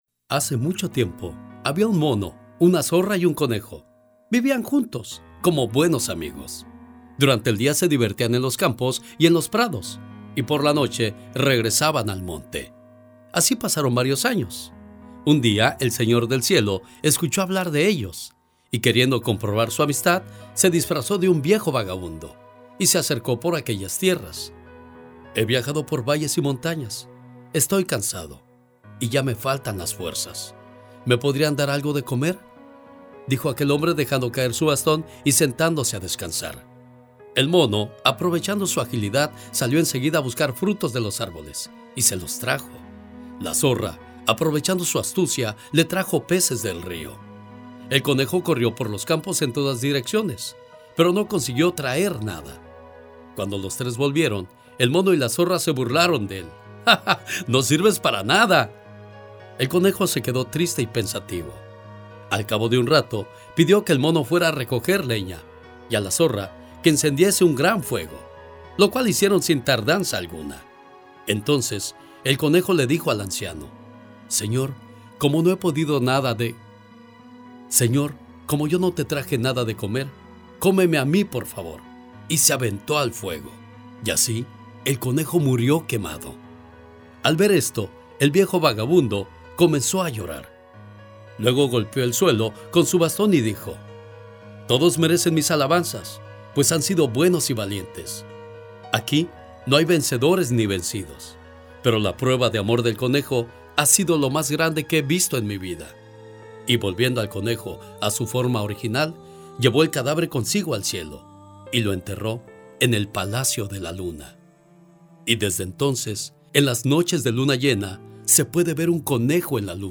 EL-CONEJO-EN-LA-LUNA-REFLEXION.mp3